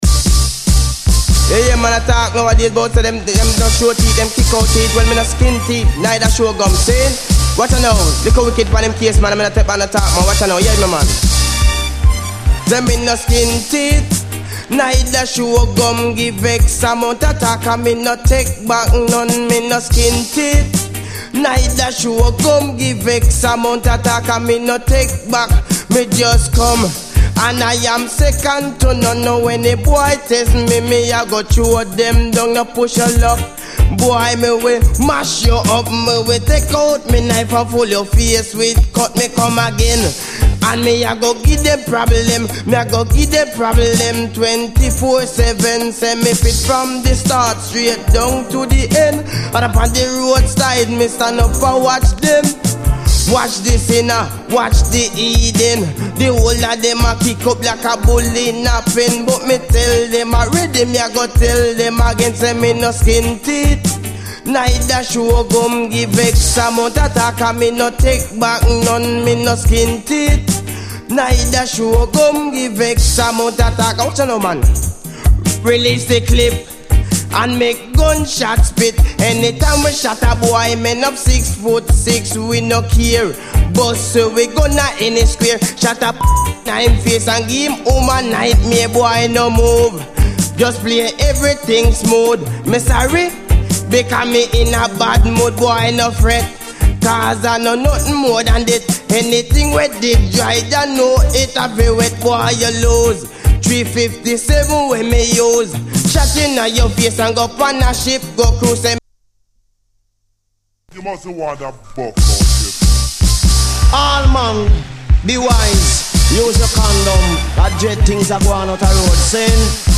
非常にUKストリート・ソウル的なシンセ・サウンドがシルキーで悩ましい！